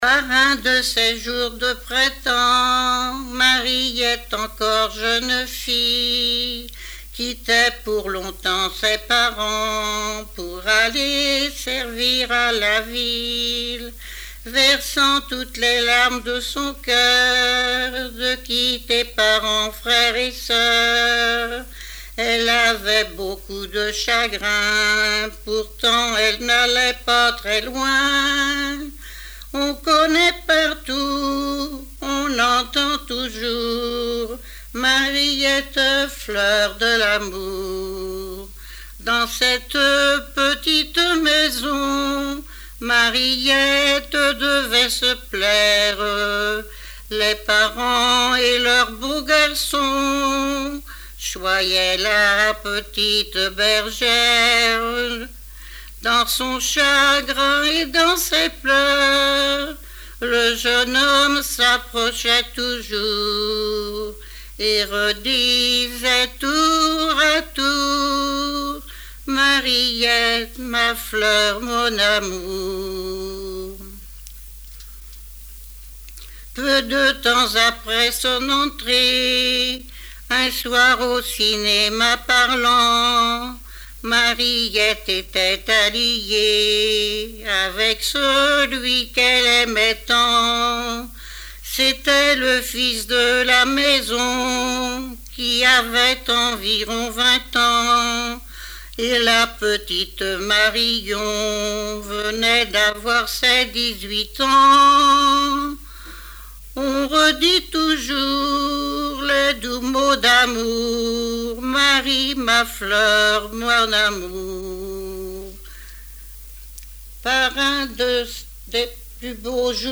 sur un timbre
Répertoire de chansons populaires et traditionnelles
Pièce musicale inédite